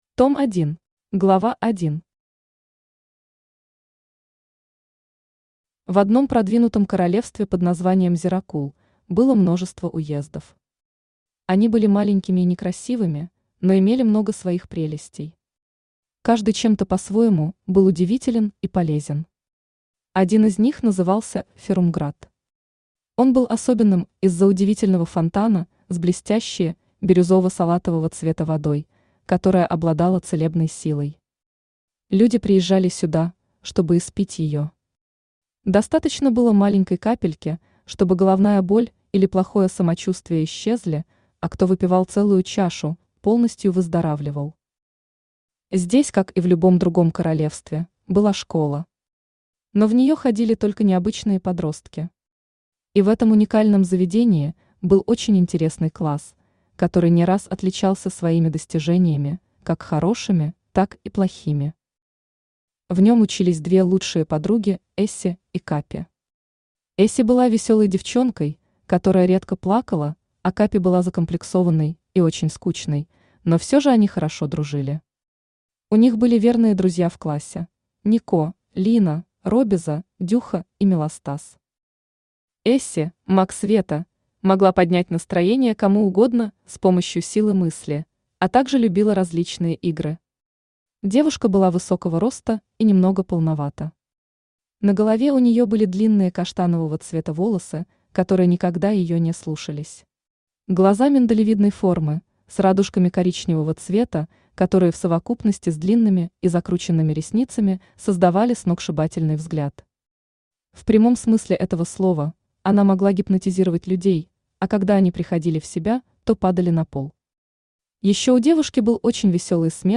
Aудиокнига Школа магии Автор Сирена Рэд Читает аудиокнигу Авточтец ЛитРес.